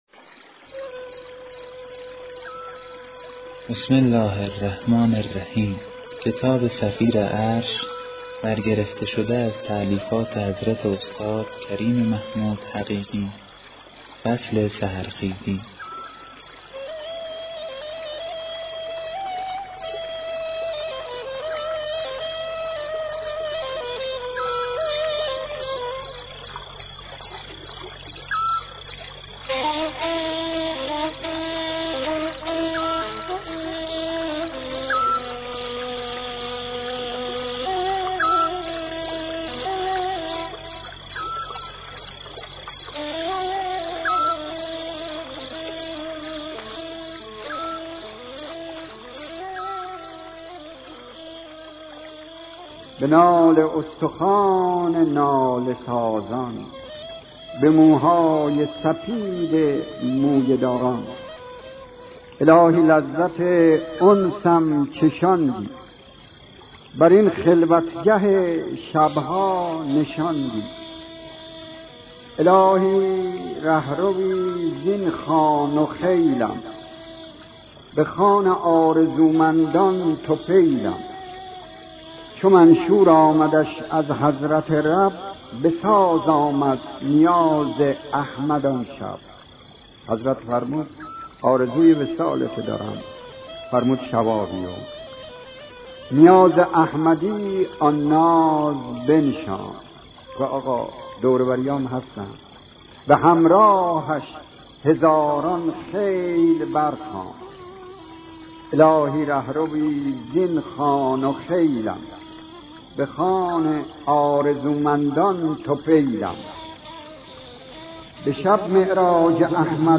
کتاب صوتی صفیر عرش، فصل سحرخیزی
جرعه هایی زلال از معارف الهی همراه با نغمه هایی بسیار زیبا از طبیعت